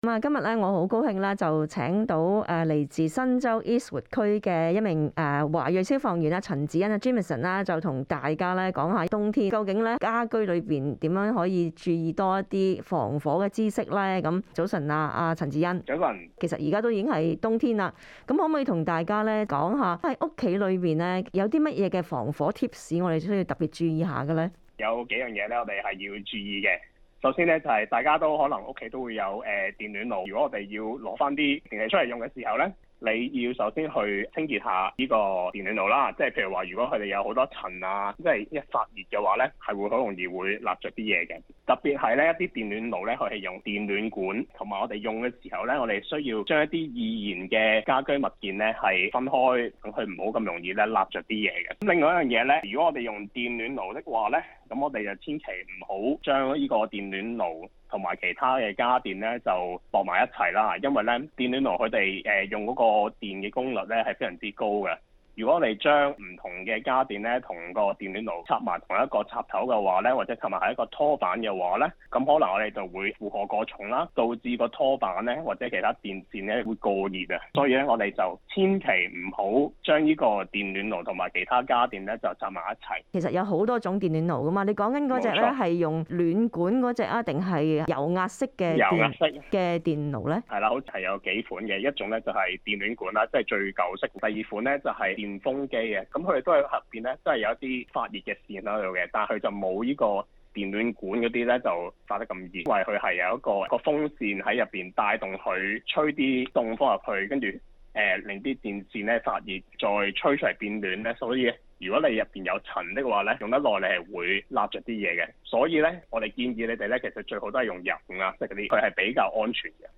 【社區訪問】